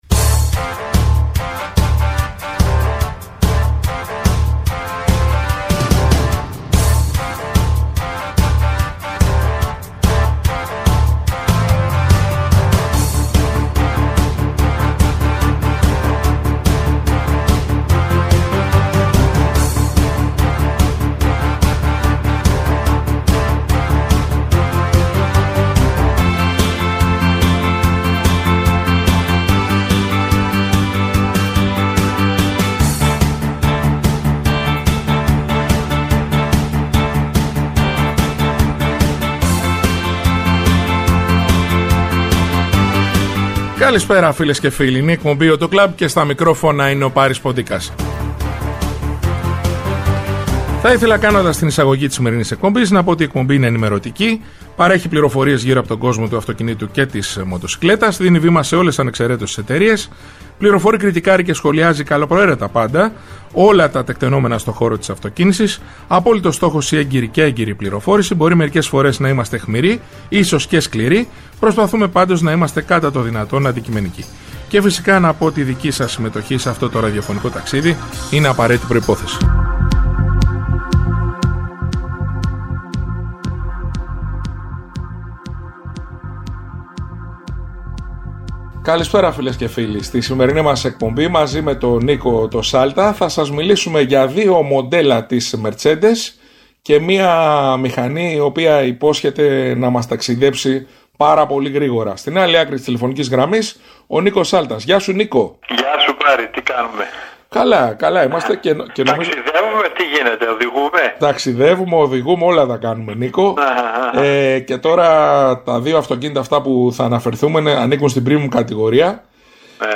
Η εκπομπή «AUTO CLUB» είναι ενημερωτική, παρέχει πληροφορίες γύρω από τον κόσμο του αυτοκινήτου και της μοτοσικλέτας, δίνει βήμα σε ολες ανεξεραίτως τις εταιρείες, φιλοξενεί στο στούντιο ή τηλεφωνικά στελέχη της αγοράς, δημοσιογράφους αλλά και ανθρώπους του χώρου. Κριτικάρει και σχολιάζει καλοπροαίρετα πάντα όλα τα τεκτενόμενα στο χώρο της αυτοκίνησης, με απόλυτο στόχο την έγκαιρη και έγκυρη πληροφόρηση για τους ακροατές, με «όπλο» την καλή μουσική και το χιούμορ.